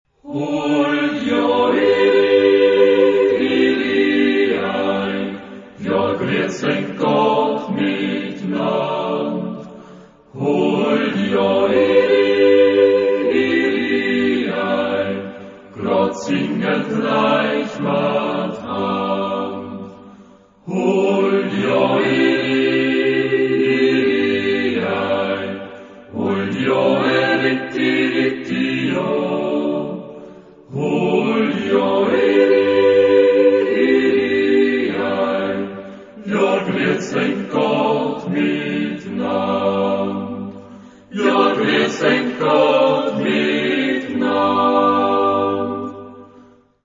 Genre-Style-Form: Secular ; Yodal ; Traditional
Mood of the piece: lively
Type of Choir: SAAB OR SATB  (4 mixed voices )
Soloist(s): Soprano (1) ad libitum  (1 soloist(s))
Tonality: G major